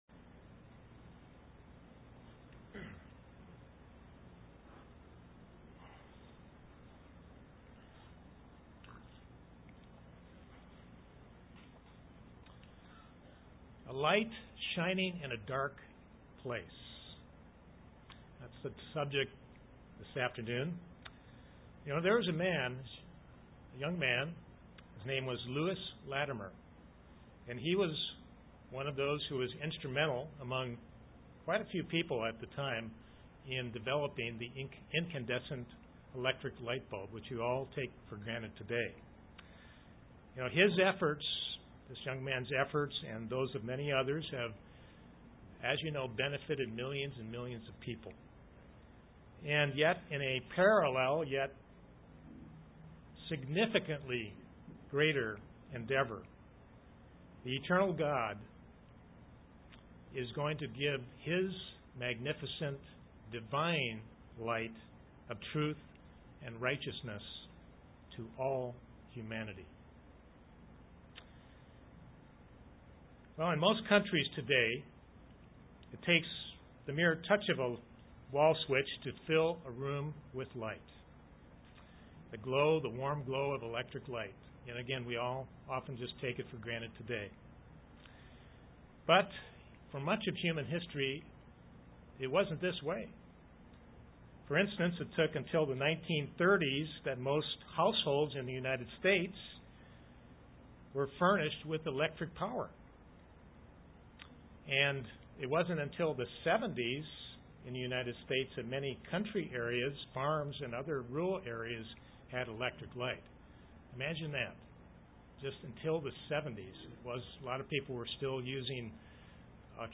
Given in Kingsport, TN London, KY
UCG Sermon Studying the bible?